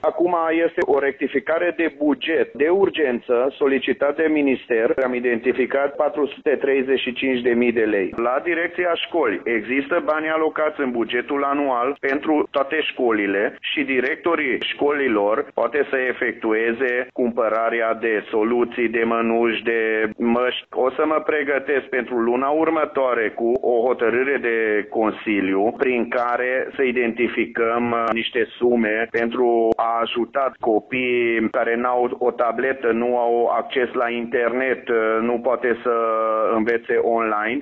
Consiliu Local Tg.Mureș a votat, astăzi, o rectificare de buget prin care, la solicitarea Ministerului Administrației Publice, a fost disponibilizată de urgență suma de 435.000 de lei pentru școli, a anunțat consilierul Csiki Zsolt: